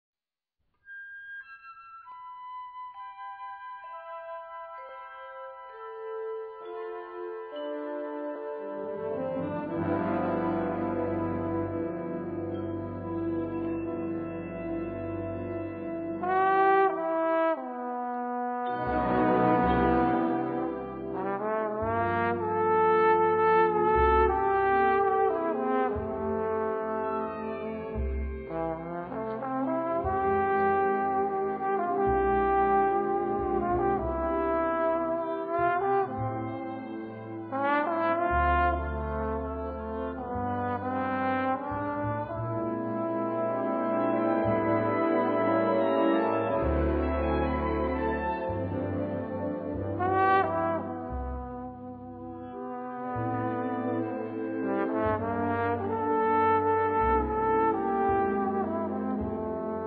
Gattung: Solostück für Posaune und Blasorchester
Besetzung: Blasorchester